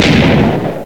old_explode.ogg